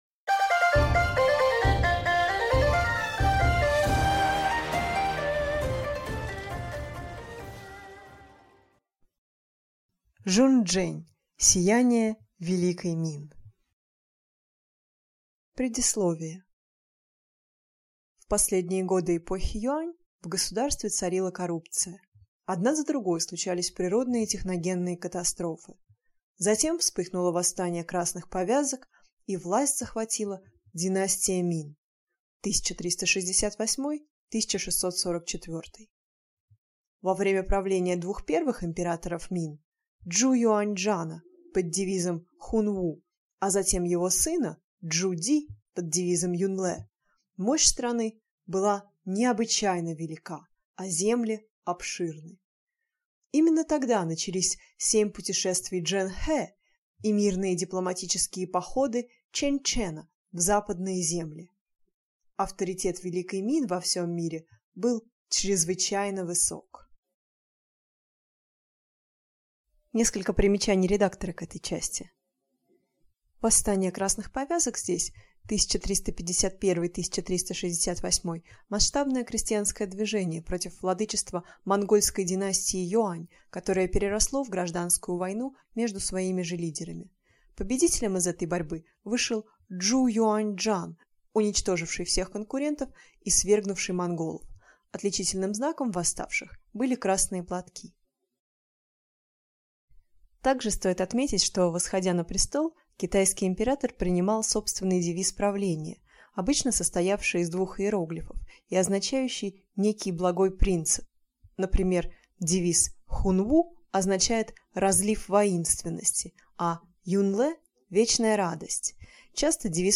Аудиокнига Сияние Великой Мин | Библиотека аудиокниг
Прослушать и бесплатно скачать фрагмент аудиокниги